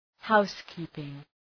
Προφορά
{‘haʋs,ki:pıŋ}